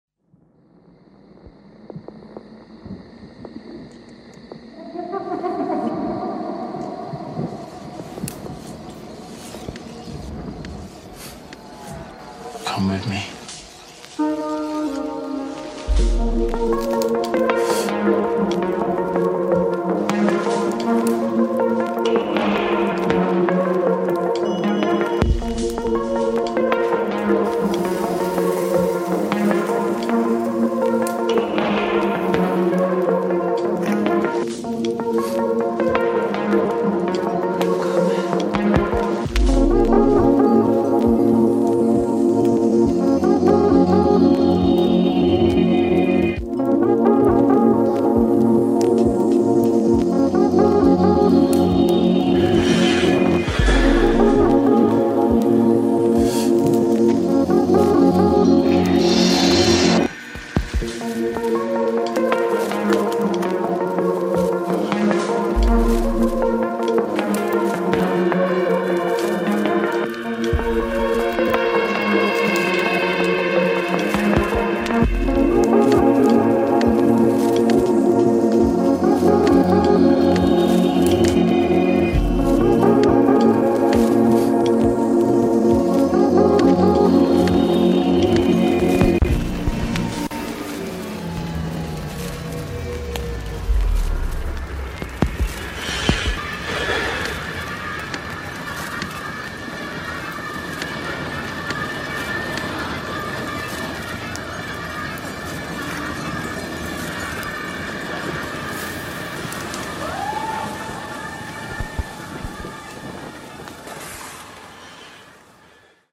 [ TECHNO | EXPERIMENTAL | BASS ] 限定180グラムヴァイナル・プレス盤！